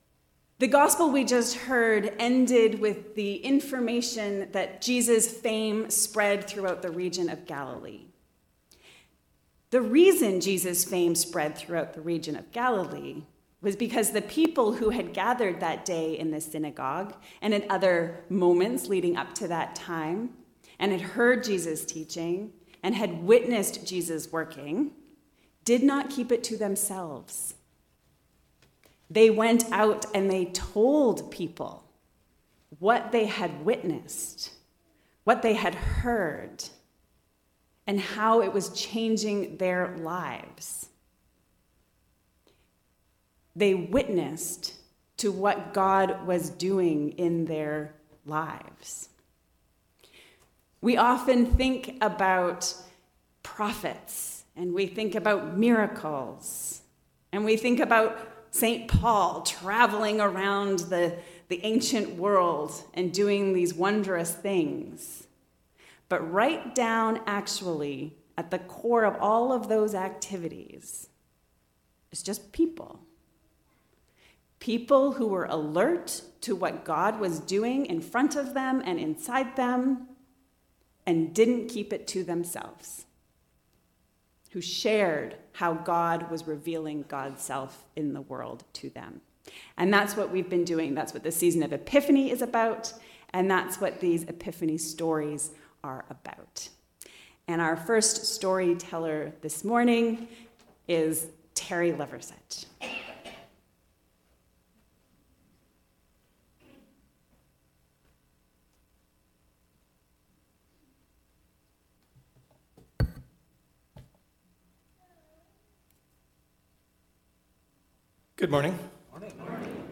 Epiphany Stories